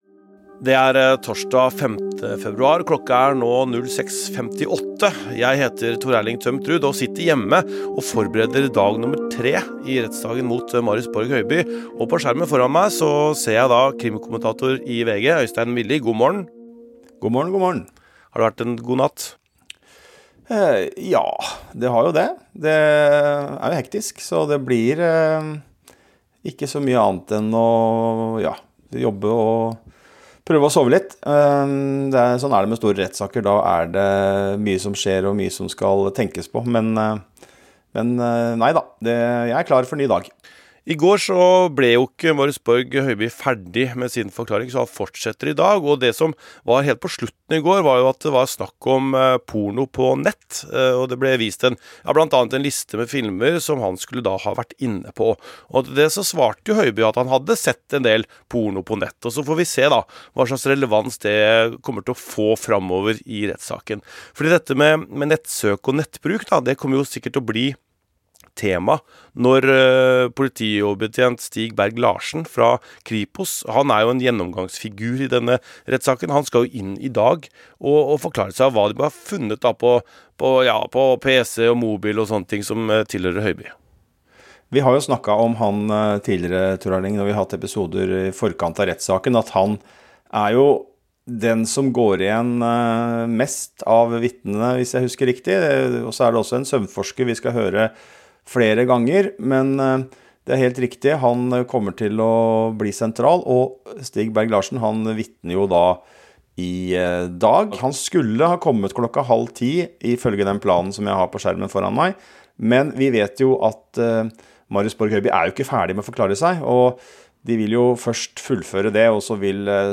rapporterer fra rettssaken